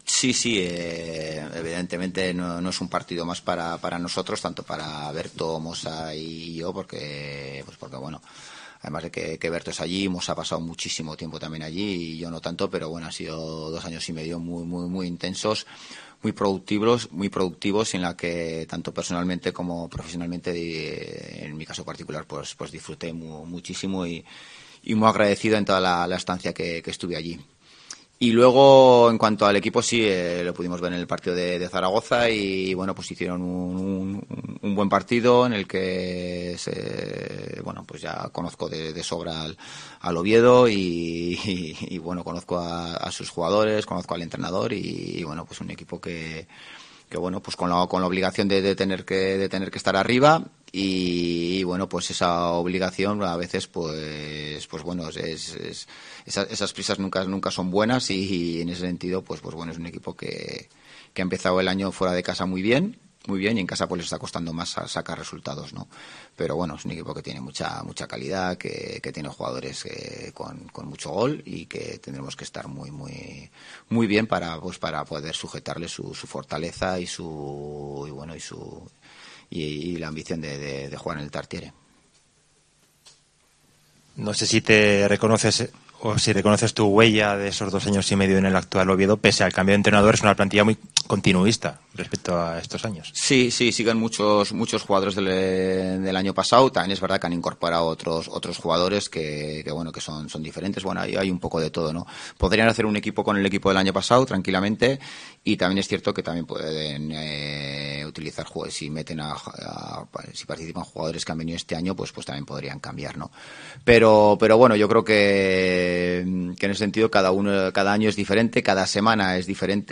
Rueda de prensa Ziganda (previa Oviedo)